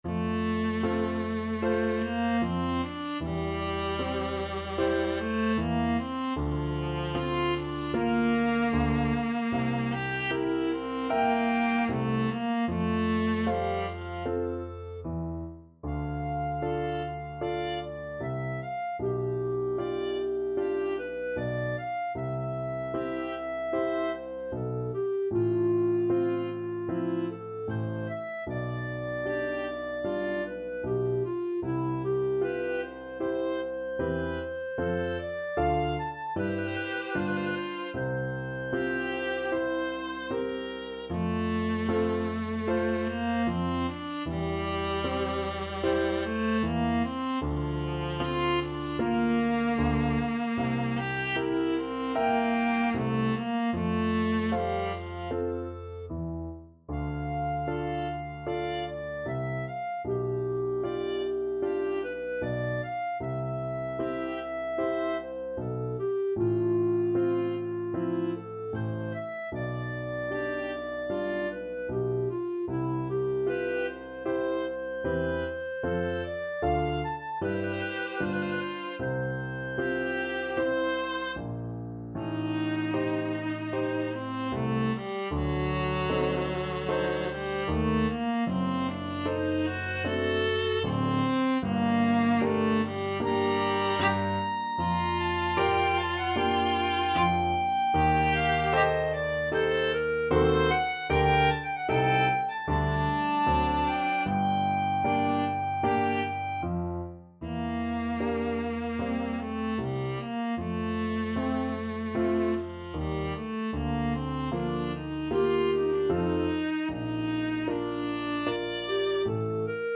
Free Sheet music for Flexible Ensemble and Piano - 2 Players and Piano
Clarinet
Viola
Piano
F major (Sounding Pitch) (View more F major Music for Flexible Ensemble and Piano - 2 Players and Piano )
4/4 (View more 4/4 Music)
Andante = c.76
Classical (View more Classical Flexible Ensemble and Piano - 2 Players and Piano Music)